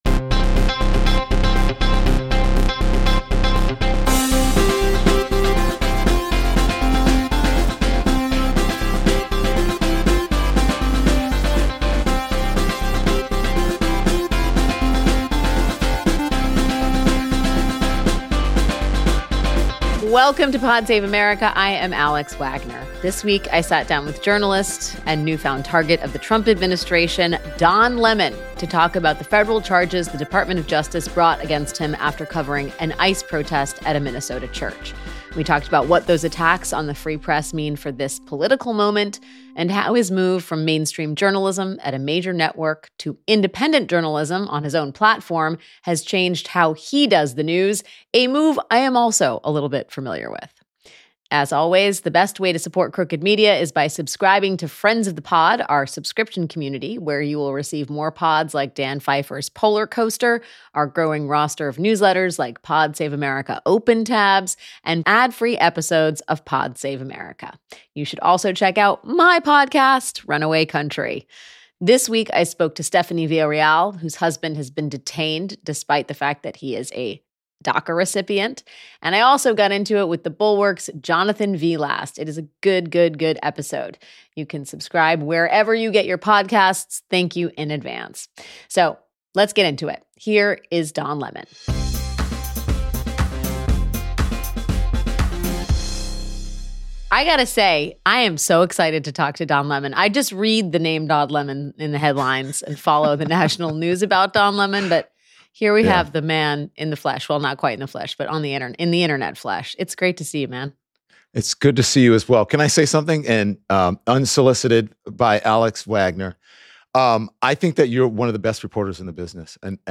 Don Lemon, the former CNN anchor turned independent journalist, talks to Alex Wagner about the charges the Department of Justice brought against him for covering an ICE protest inside a Minnesota church. Don shares new details about the days leading up to his arrest and the administration's war against a free and independent press. Then, they talk about the differences between independent and mainstream journalism, how MAGA media differs from the media on the left, and what's next for Lemon and the "Lemon Heads."